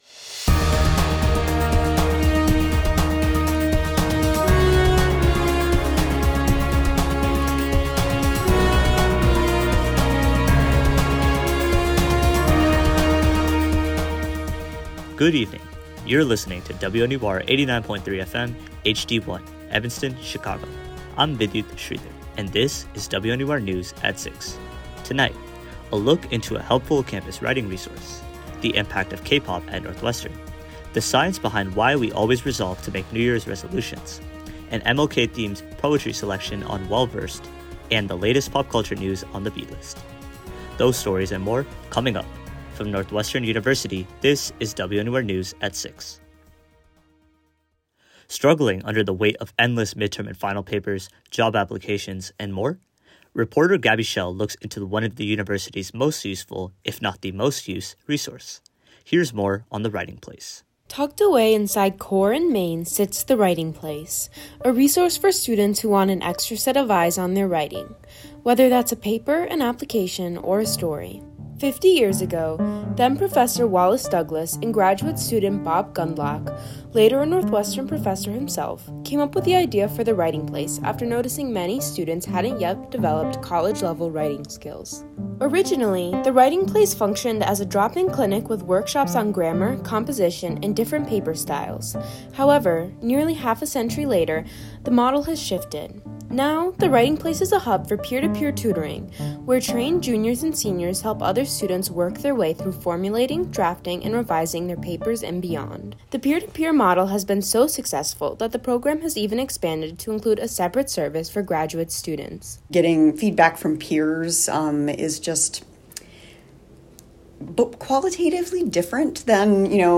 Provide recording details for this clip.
WNUR News broadcasts live at 6 pm CST on Mondays, Wednesdays, and Fridays on WNUR 89.3 FM.